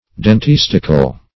Search Result for " dentistical" : The Collaborative International Dictionary of English v.0.48: Dentistic \Den*tis"tic\, Dentistical \Den*tis"ti*cal\, a. Pertaining to dentistry or to dentists.